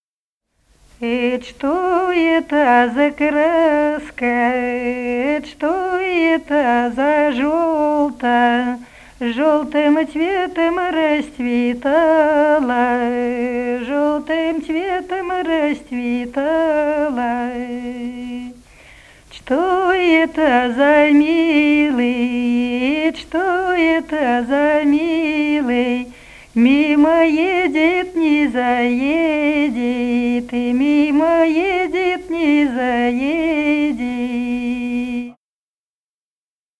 Народные песни Смоленской области
«Эт' что это за краска» (лирическая).